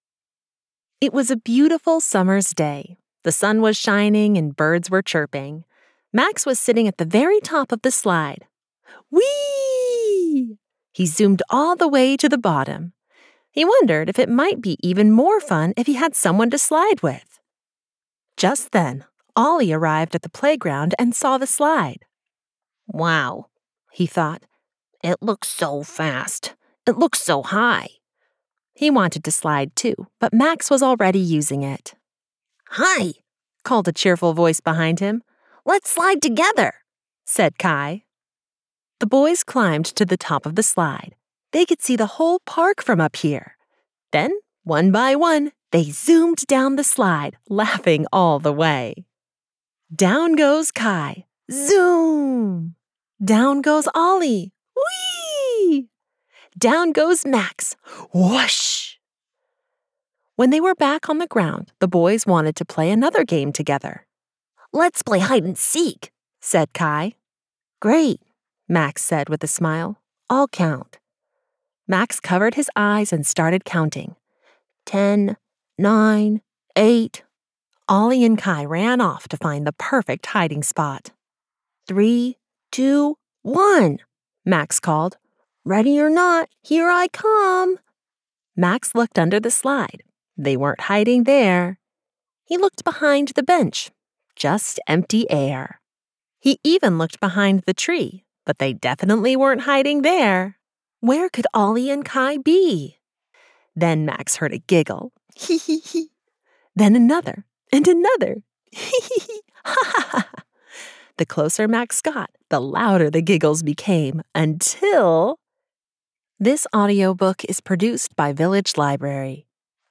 This gentle, rhythmic story invites children into a world where belonging feels natural, differences are welcomed and kindness nurtures connection.